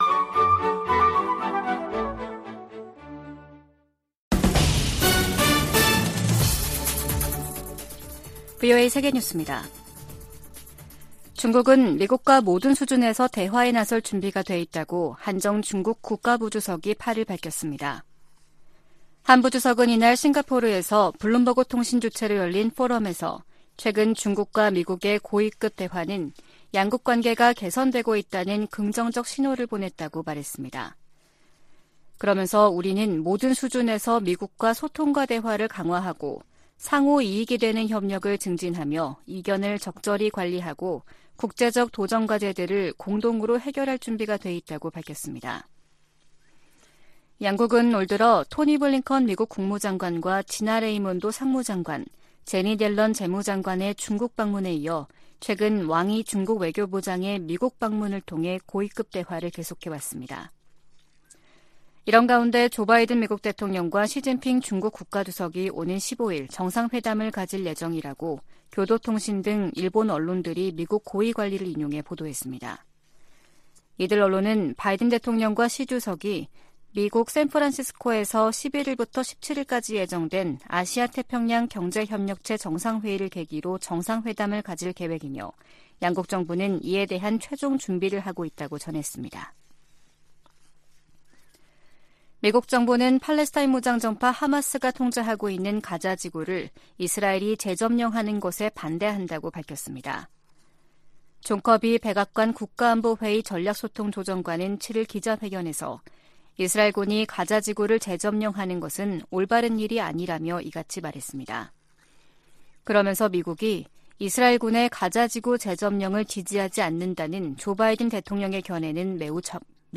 VOA 한국어 아침 뉴스 프로그램 '워싱턴 뉴스 광장' 2023년 11월 9일 방송입니다.